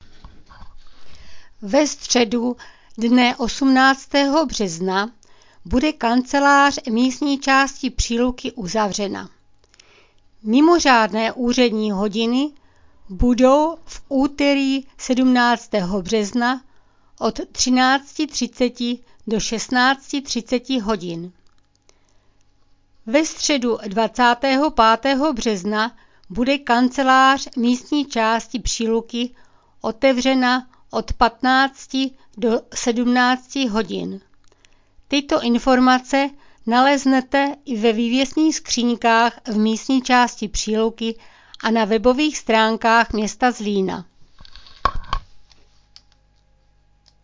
Hlášení místního rozhlasu